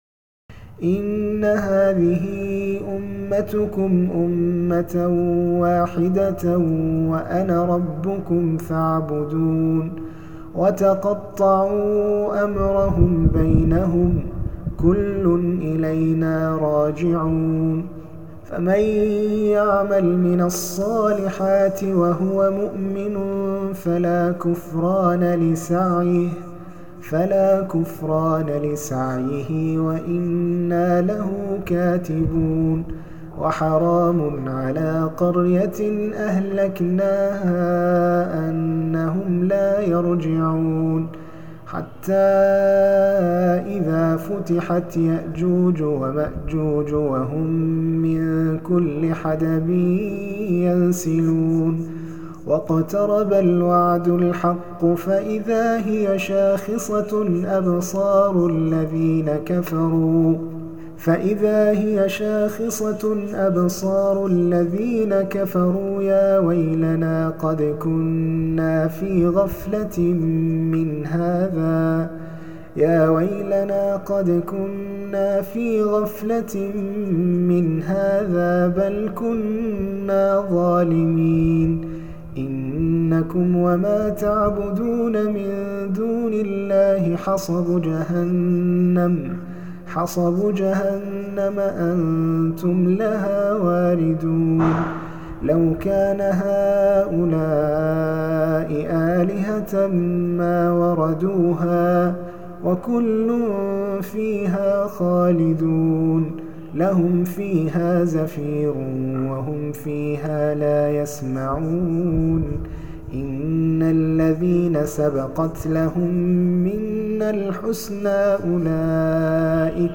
خواتم سورة الأنبياء تراويح 5 رمضان